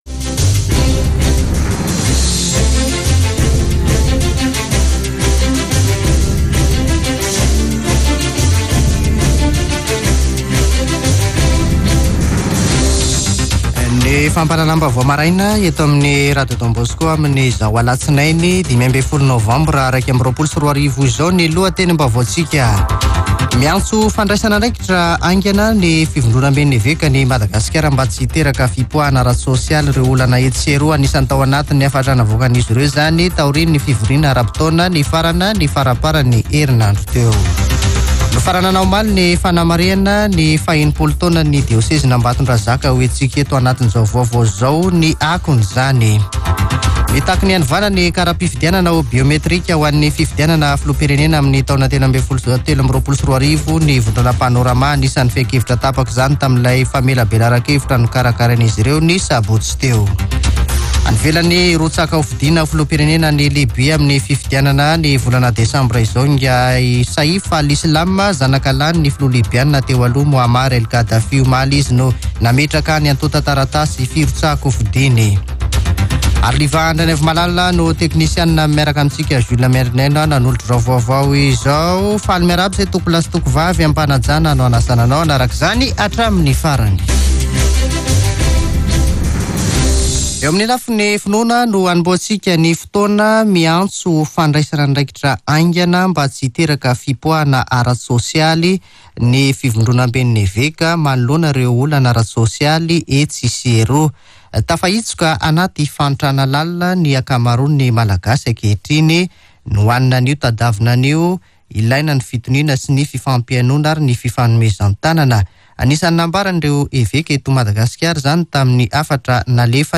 [Vaovao maraina] Alatsinainy 15 novambra 2021